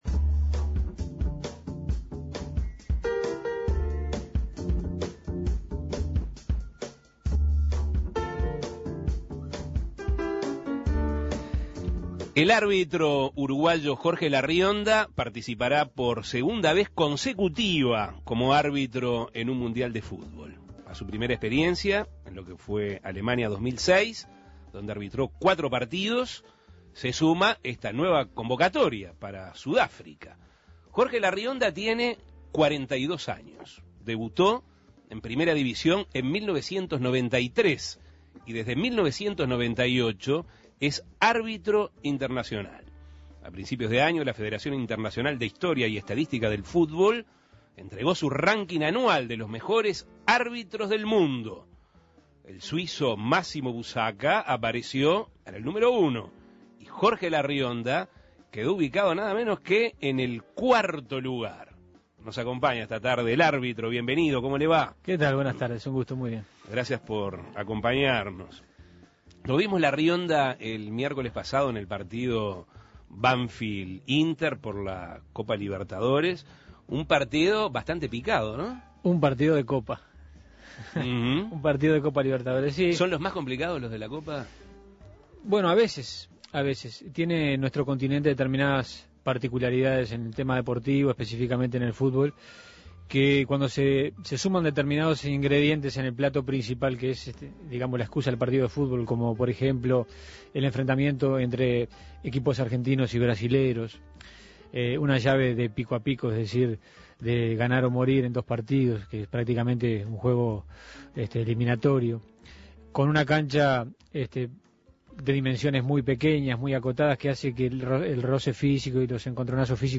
Larrionda habló, entre otras cosas, sobre sus inicios en el arbitraje, sus referentes y sus apreciaciones sobre las reglas del juego actuales. Escuche la entrevista.